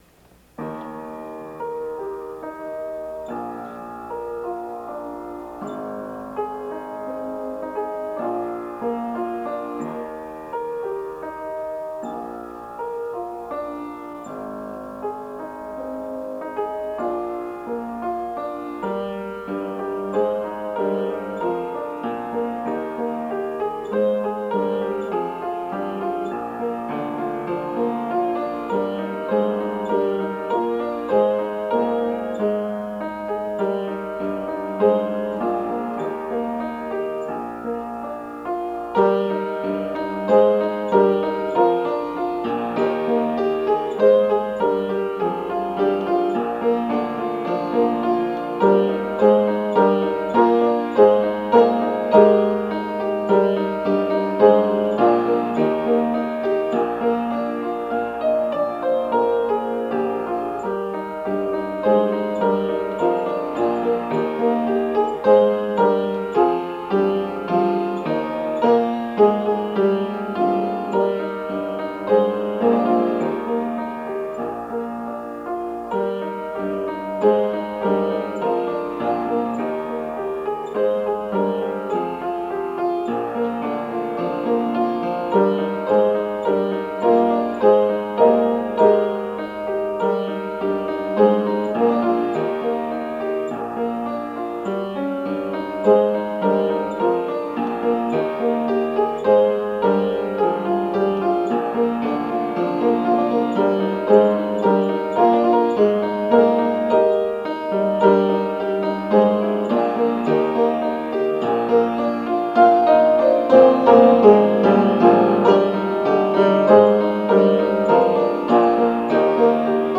This is the only hymn I've written.